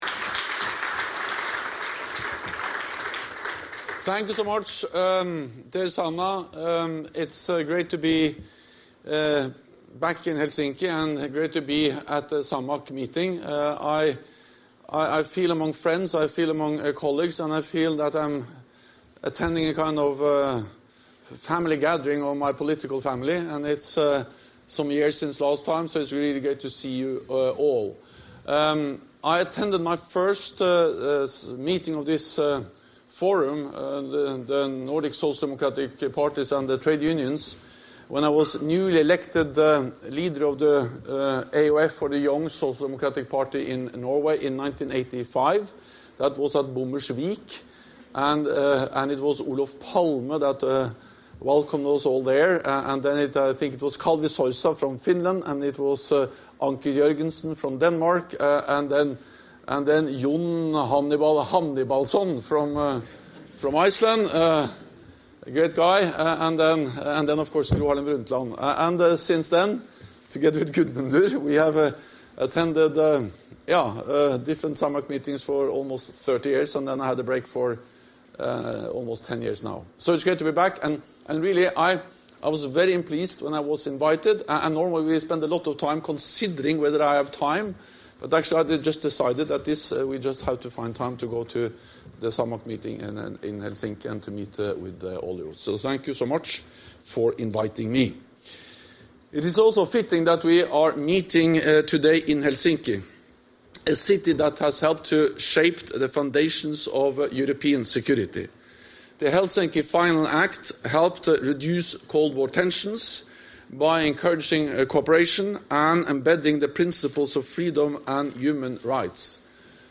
Speech
by NATO Secretary General Jens Stoltenberg at the SAMAK Nordic Summit in Helsinki